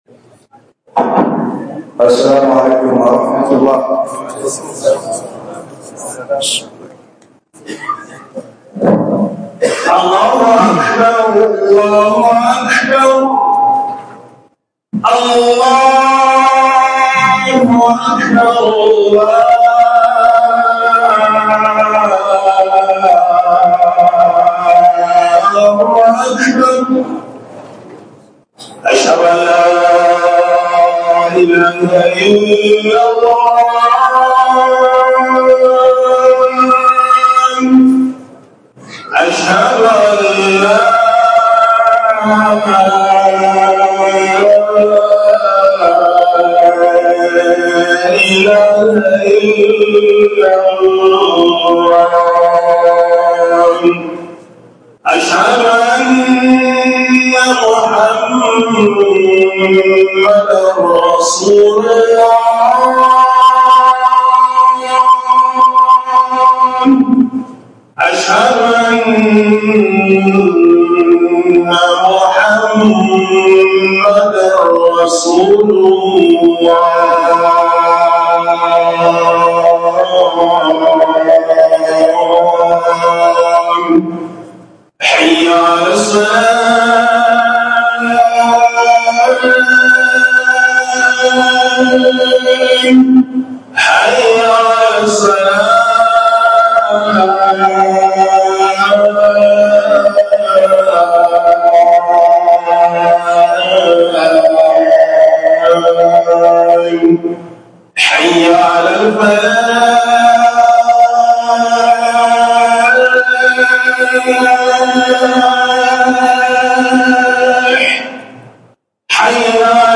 Hudubar Juma'a 7 mars_ 2025